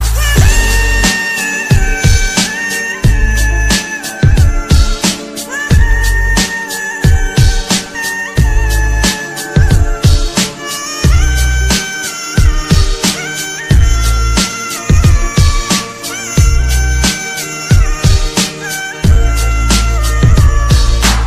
اهنگ احساسی ترکی با ریتم تند